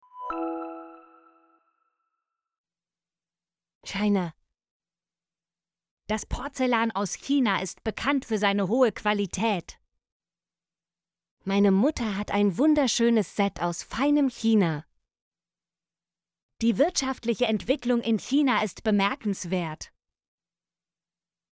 china - Example Sentences & Pronunciation, German Frequency List